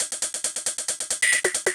K-5 LofiLoop.wav